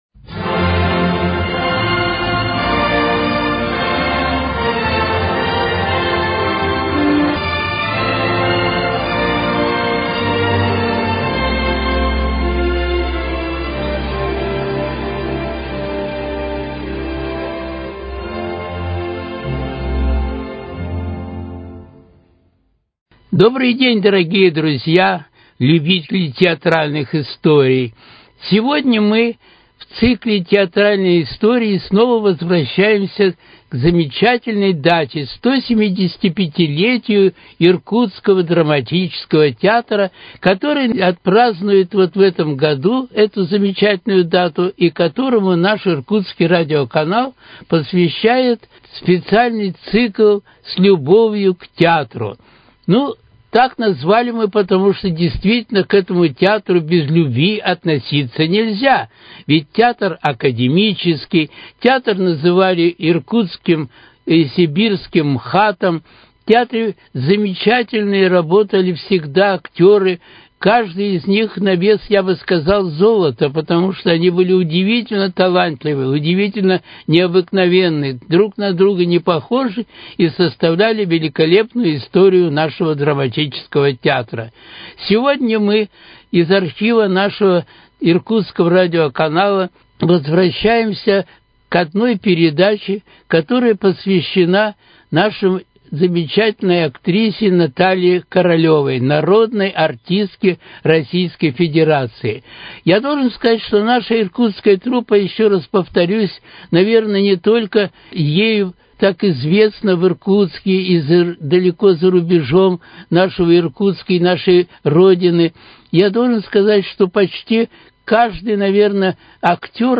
В этом выпуске передача из архива Иркутского радио.